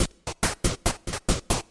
描述：d小鼓线。
Tag: 140 bpm Electronic Loops Drum Loops 295.49 KB wav Key : Unknown